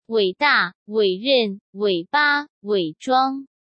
wěi dàwěi rènwěi bāwěi zhuāng